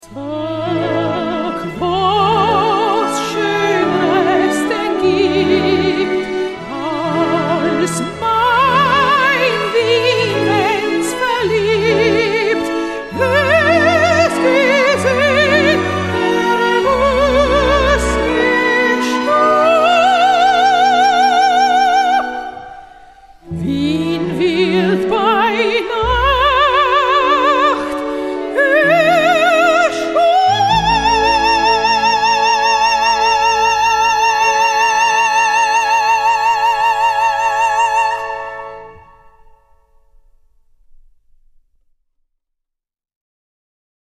The CD contains both vocal and orchestral pieces.